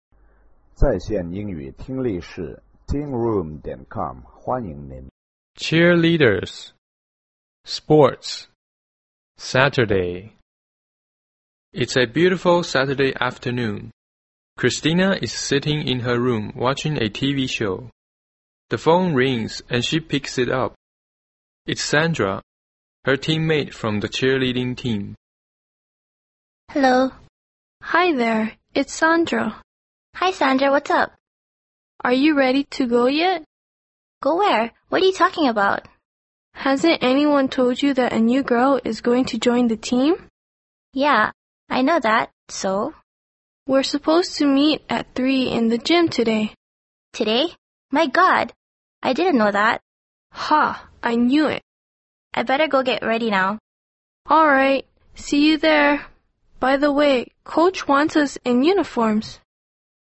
EPT美语 体育（对话） 听力文件下载—在线英语听力室